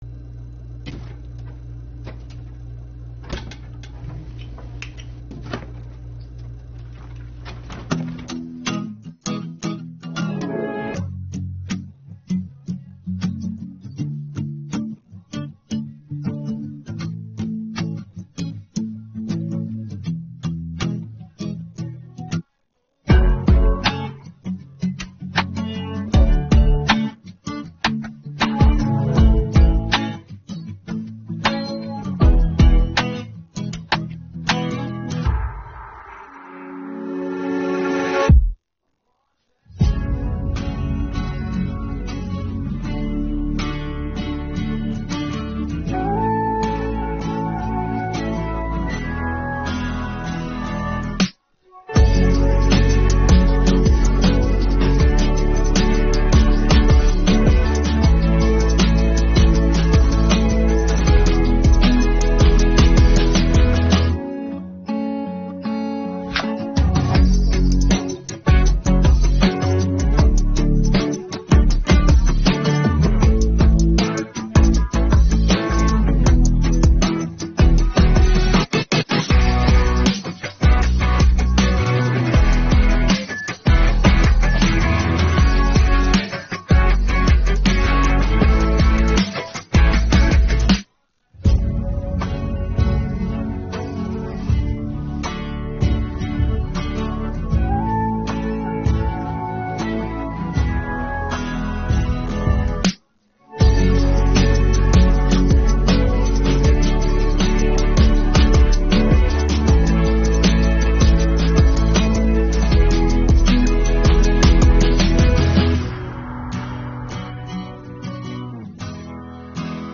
це позитивна пісня
українське караоке